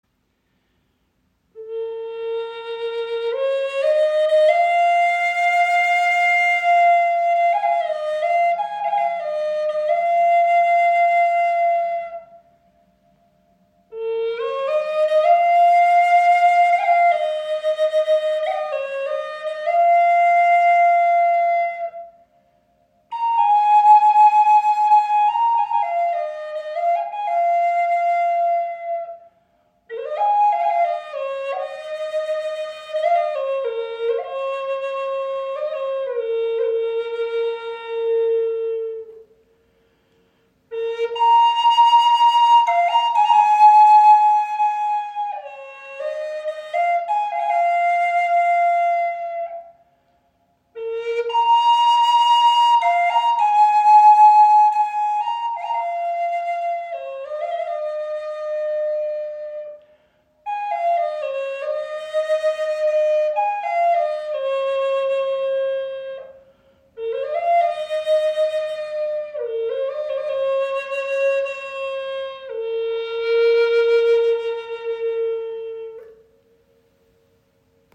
• Icon Handgefertigt aus Indian Rosewood mit Ahorn Windblock
Entdecke eine einzigartige Gebetsflöte aus indischem Palisanderholz, gestimmt auf B♭ (440 Hz), mit Ahornakzenten und Onyx-Cabochon auf dem Adler-Windblock.
Jede Flöte wird mit präziser Handarbeit gestimmt, sodass sie harmonische, klare Töne entfaltet, die Herz und Seele berühren.